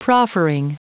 Prononciation du mot proffering en anglais (fichier audio)
Prononciation du mot : proffering